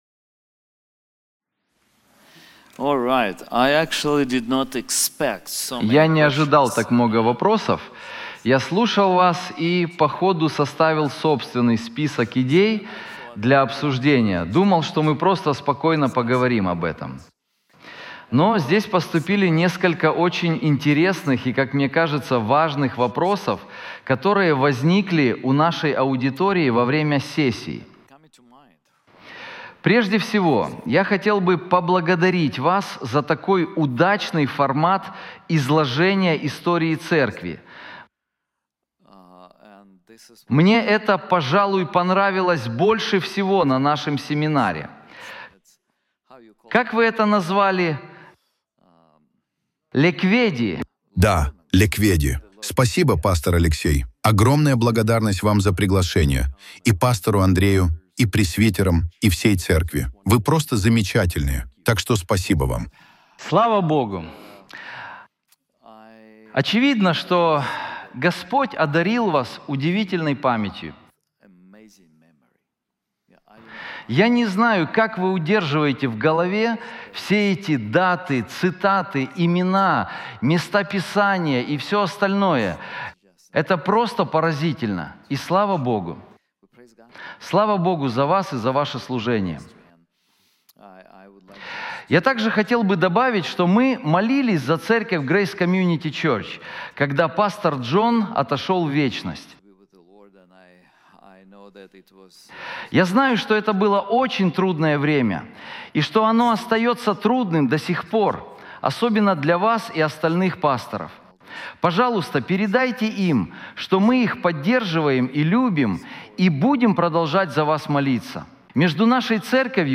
Вопросы и ответы